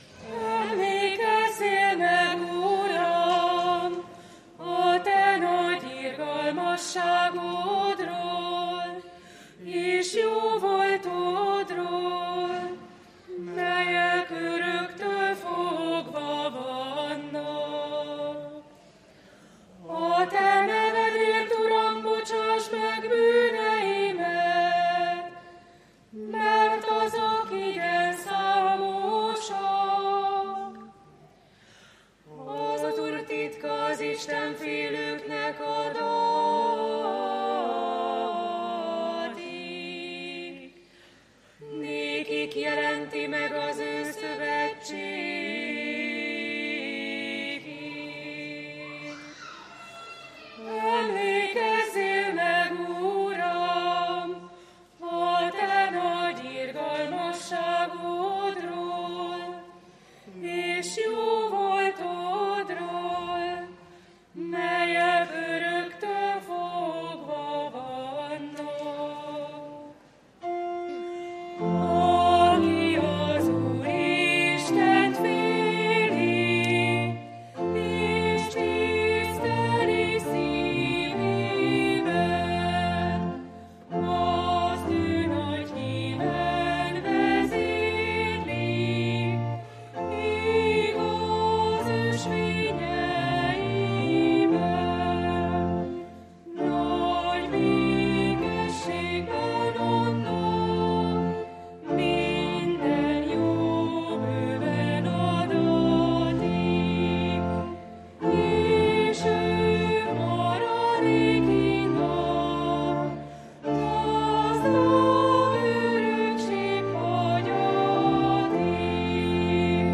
Mi csak tegnapiak vagyunk – Budahegyvidéki Református Egyházközség